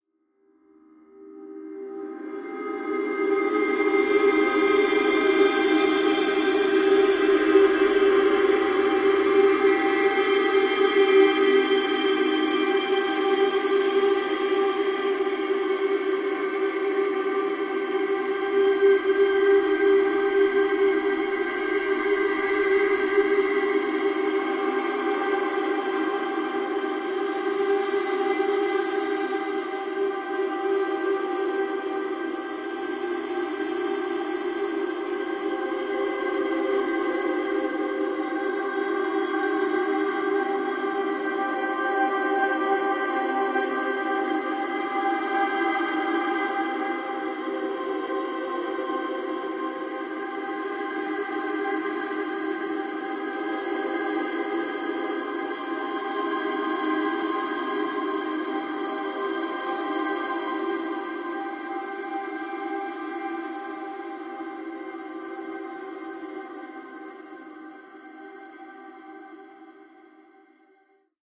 Ambiance atmosphere vocal happy joyful fantasy